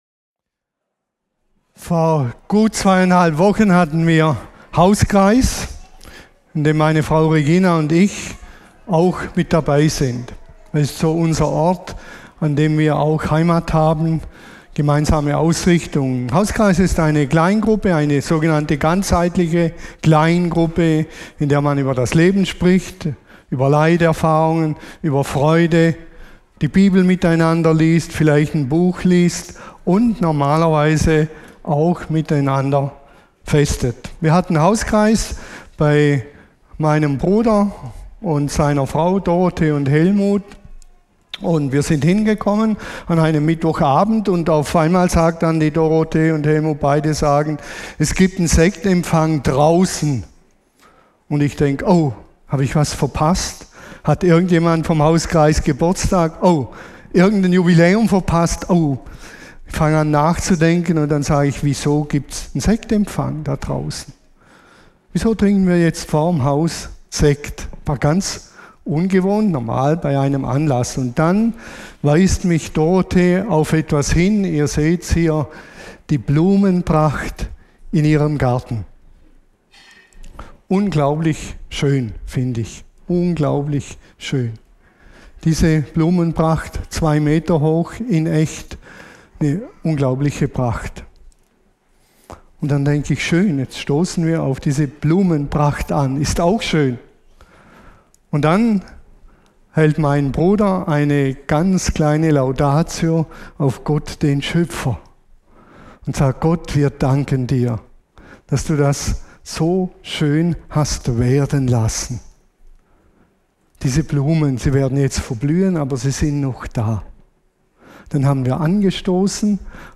Predigt als Audio